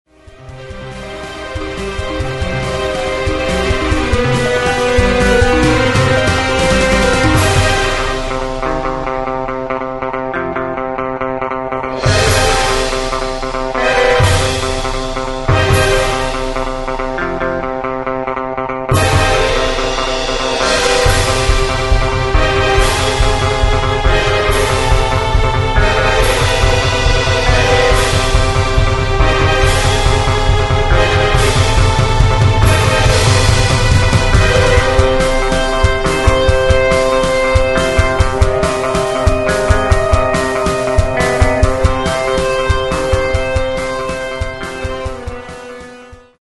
Часть инструментальной оркестровки, полная версия по ссылке